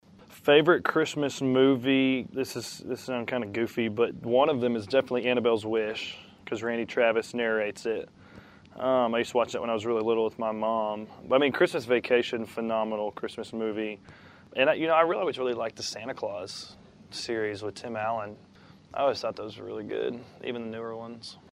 Audio / PARKER MCCOLLUM TALKS ABOUT HIS FAVORITE CHRISTMAS MOVIES.